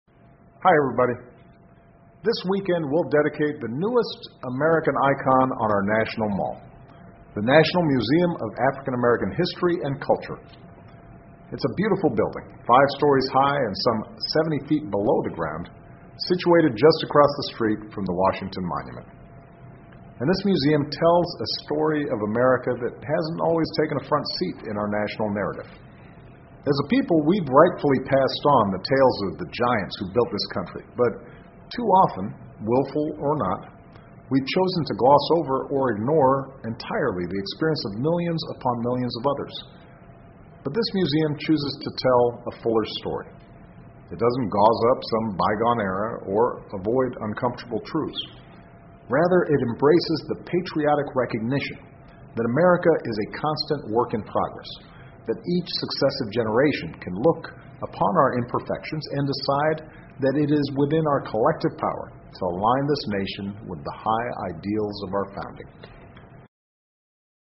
奥巴马每周电视讲话：总统庆祝美国非裔博物馆开幕（01） 听力文件下载—在线英语听力室